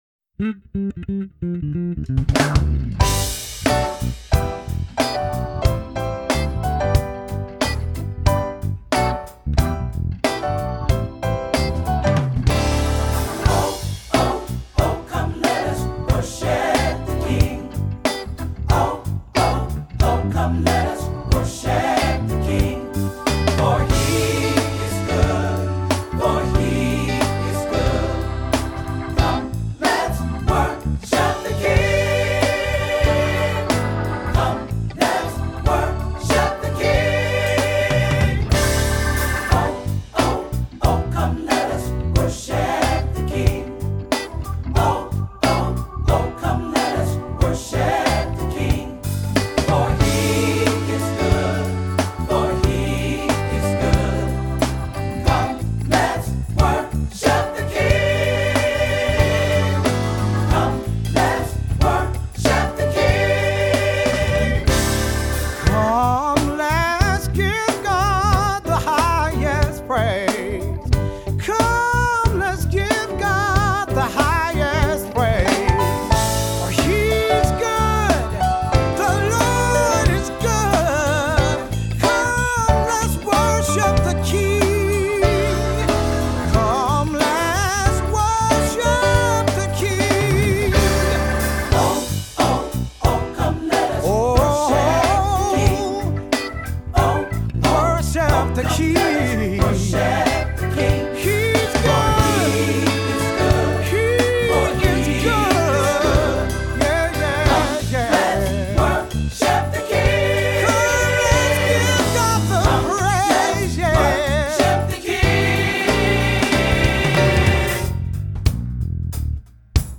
Voicing: SAT or Three-part Choir; Solo; Cantor; Assembly